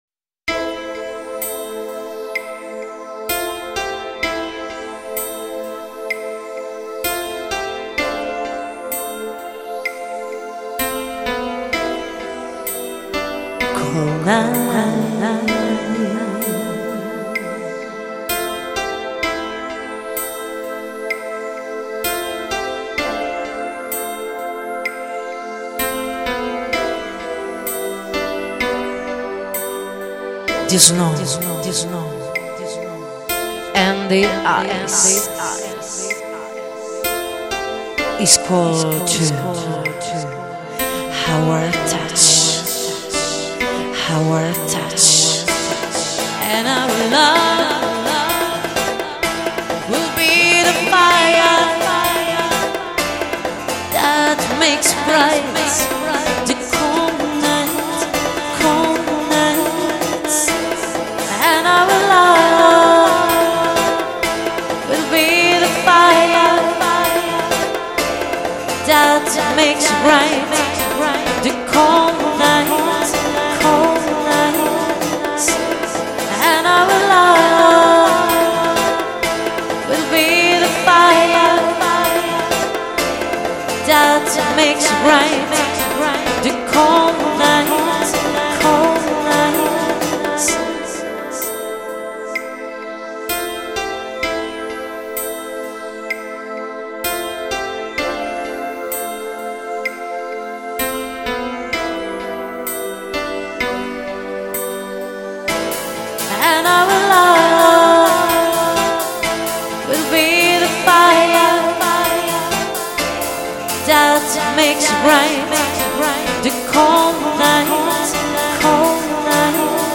unplugged